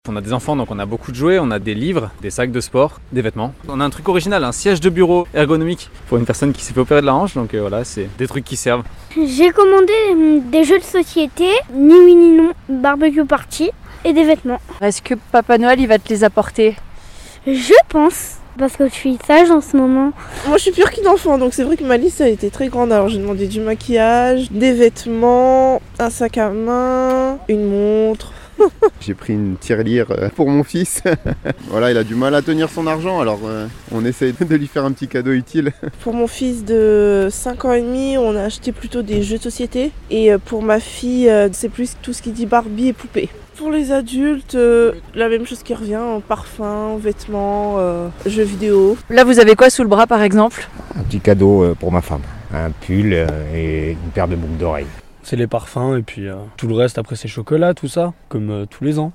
ITC Micrott retardataires 2- Noel 2024 (1’’)